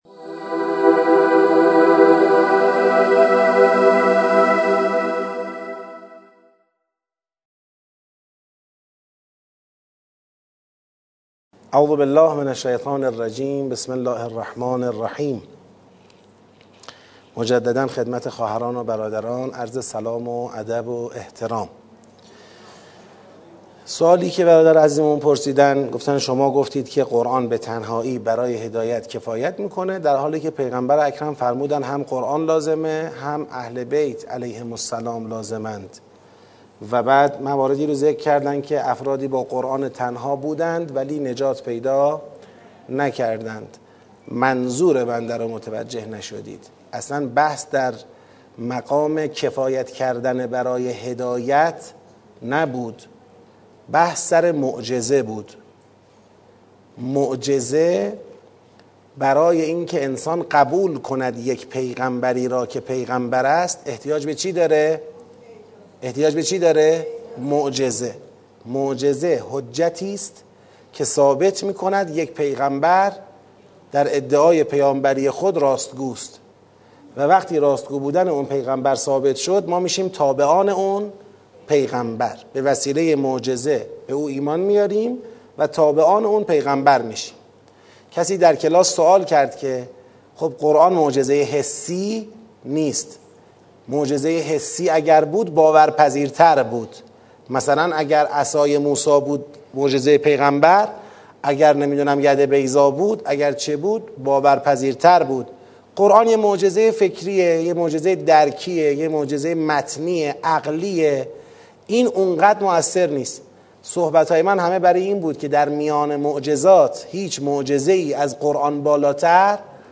سطح پنج این دوره از خرداد تا شهریور ۱۴۰۱ طی ۱۲ هفته در مسجد ولیعصر (عج) شهرک شهید محلاتی برگزار شد.